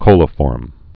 (kōlə-fôrm, kŏlə-)